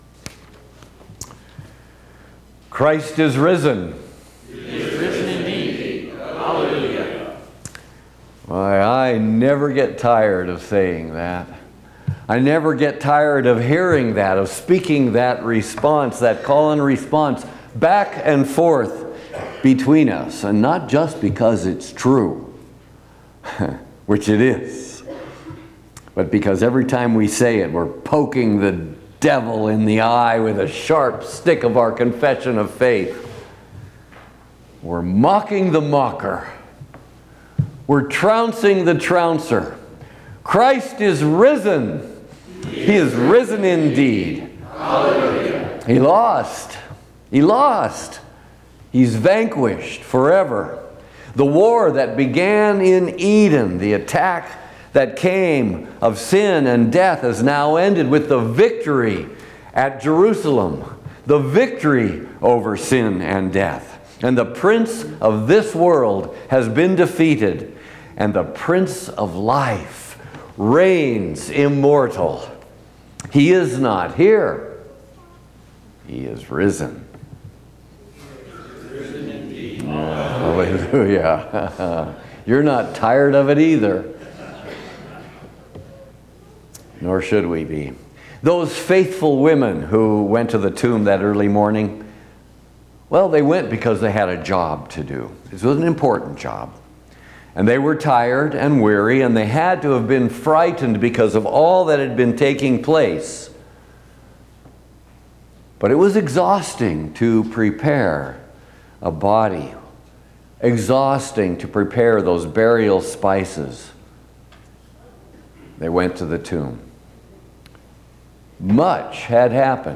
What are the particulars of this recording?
Easter Sunrise Service&nbsp